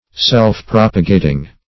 Search Result for " self-propagating" : The Collaborative International Dictionary of English v.0.48: Self-propagating \Self`-prop"a*ga`ting\, a. Propagating by one's self or by itself.